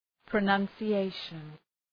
{prə,nʌnsı’eıʃən}